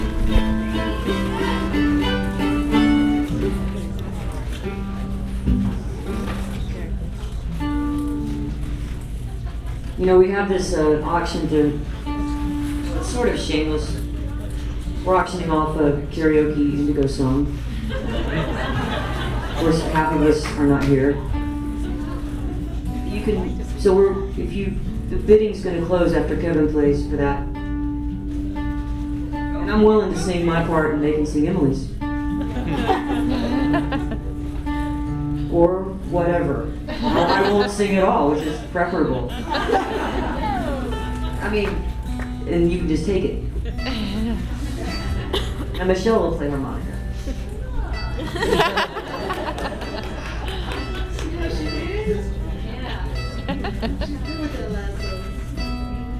lifeblood: bootlegs: 2003-08-24: red light cafe - atlanta, georgia (atlanta harm reduction center benefit) (amy ray)